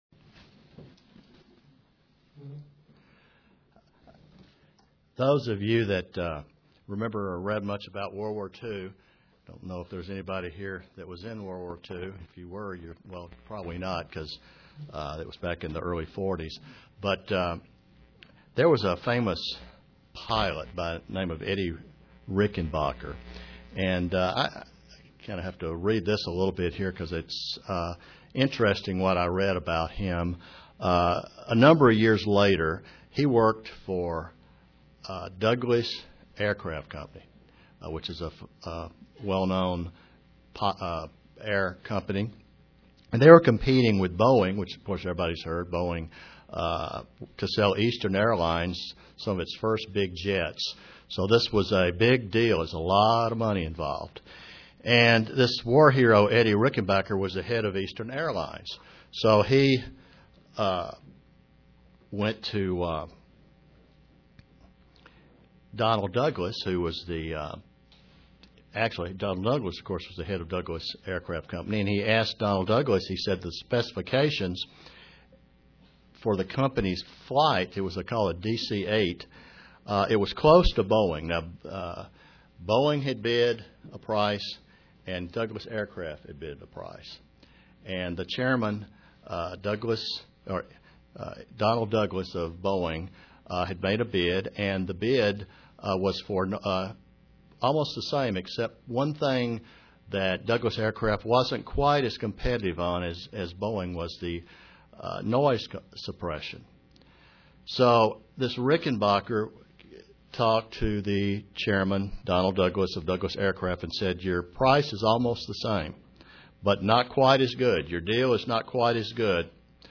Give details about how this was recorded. The life of a Christian is a life of developing godly character and it is very importance to realize the importance of the right type of character in our lives. (Presented to the Kingsport TN, Church)